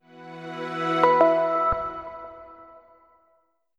Startup Sound.wav